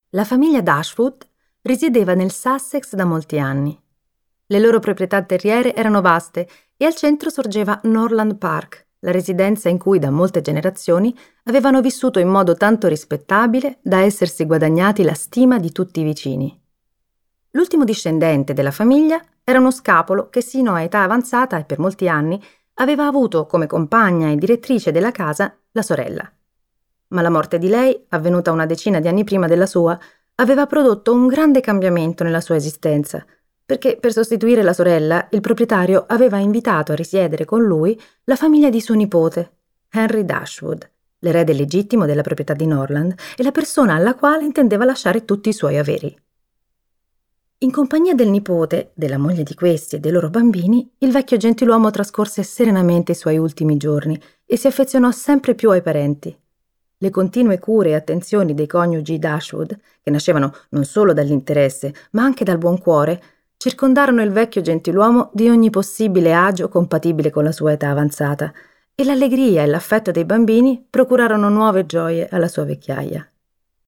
letto da Paola Cortellesi